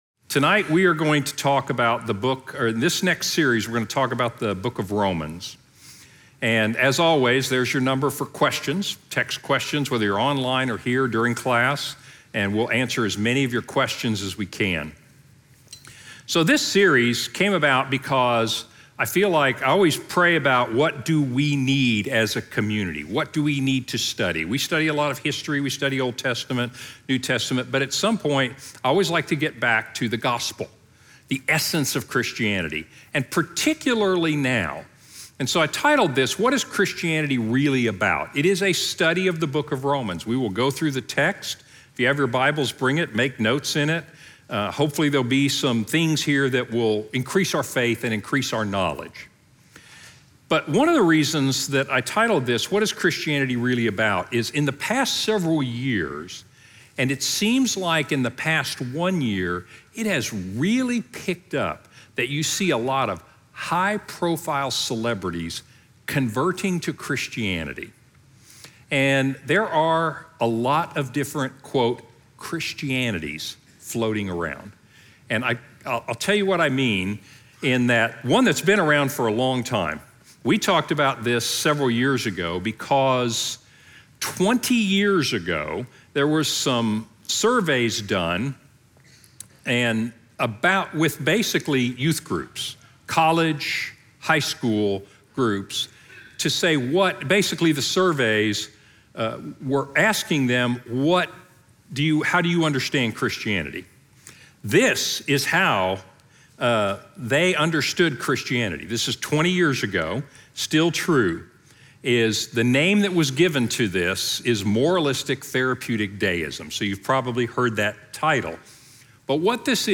Wednesday Night Teaching